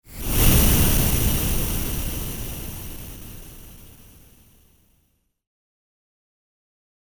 動き ノイズ加工
/ F｜演出・アニメ・心理 / F-22 ｜Move whoosh(動く、移動する)
シュォーシュオーーー